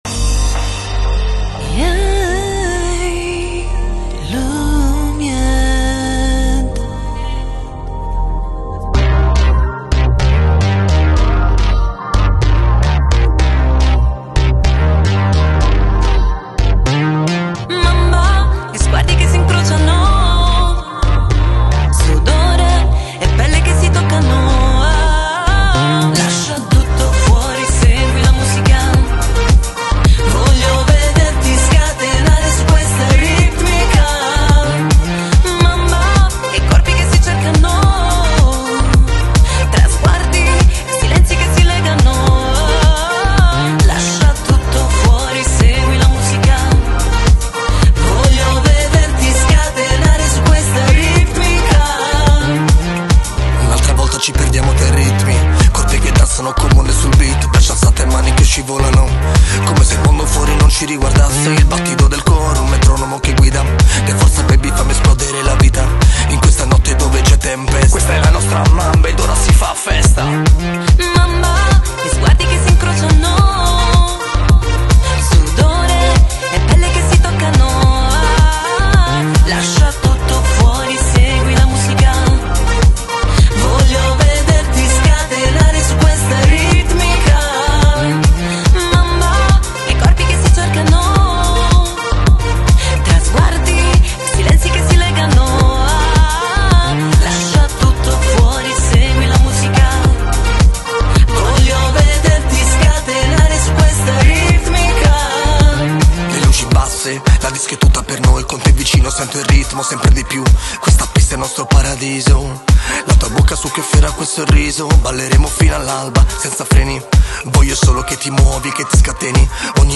trio musicale apulo-romano
Classica ▸ Musica sacra